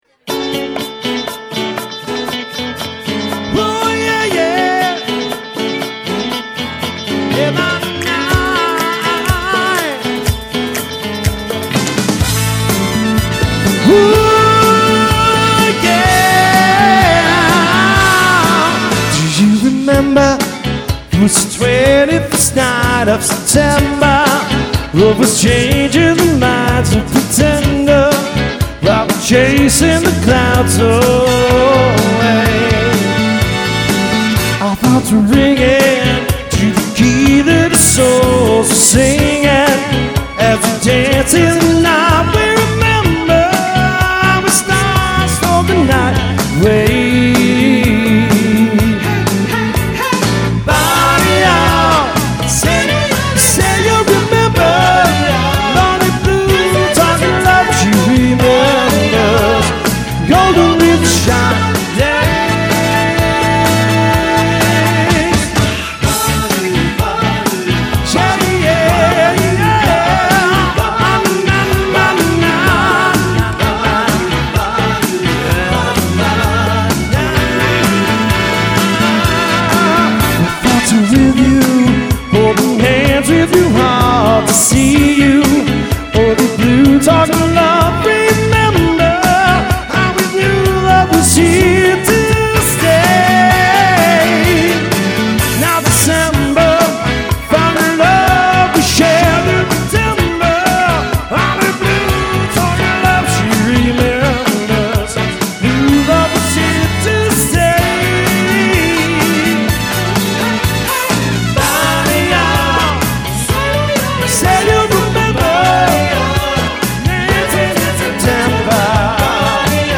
Acoustic/electric guitars and one singer.
(guitar, vocals with backing tracks)